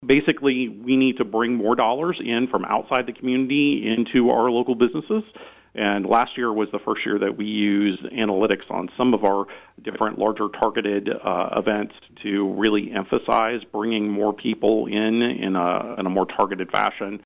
an interview on KVOE’s Talk of Emporia Monday morning